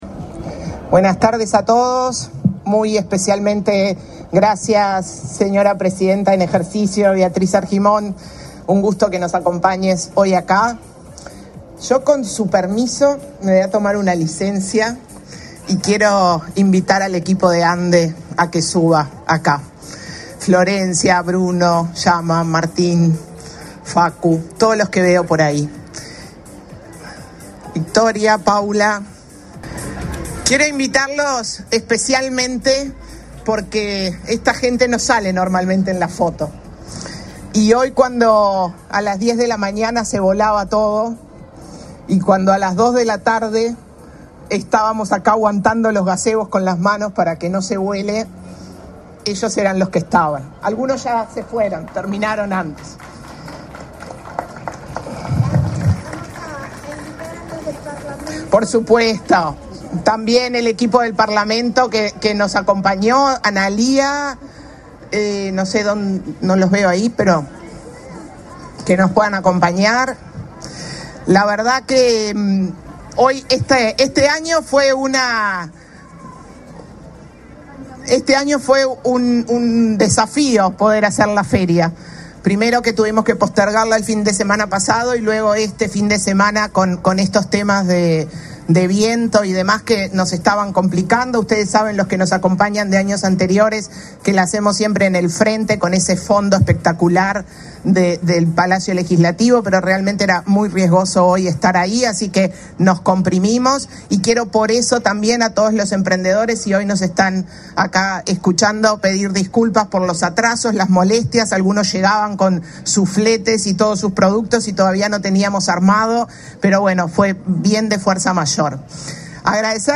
Declaraciones a la prensa de la presidenta en ejercicio, Beatriz Argimón, y del director de la ANDE, Martín Ambrosi
La presidenta de la República en ejercicio, Beatriz Argimón, participó, este 18 de noviembre, en la apertura de la Feria Emprendedora de la Agencia Nacional de Desarrollo (ANDE). En la oportunidad, Argimón y el el director de ANDE, Martín Ambrosi, realizaron declaraciones a la prensa.